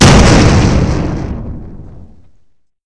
extinguisher_explosion.2.ogg